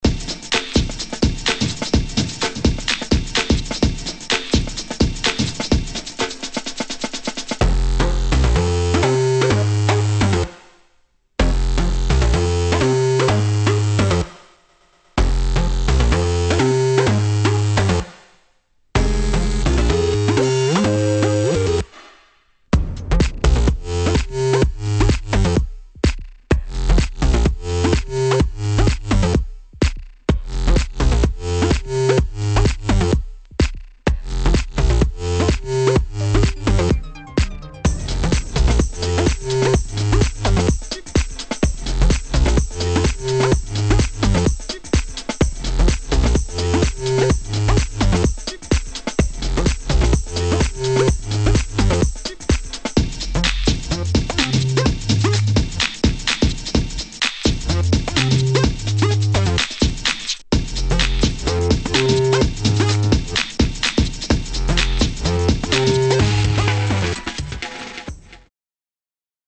[ HOUSE | TECHNO | ELECTRO ]